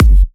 edm-kick-21.wav